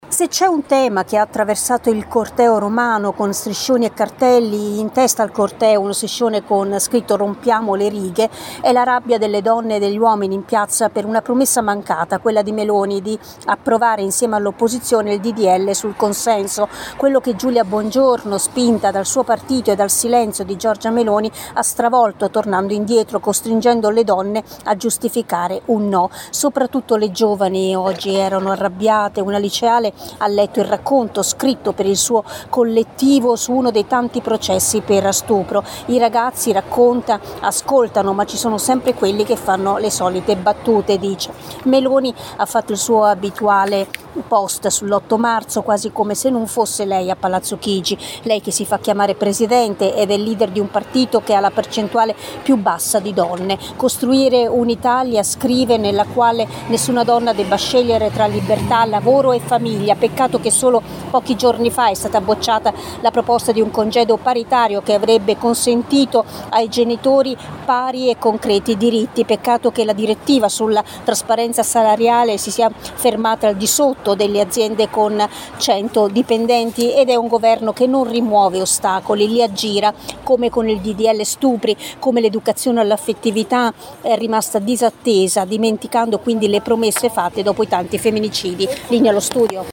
Il servizio dell’inviata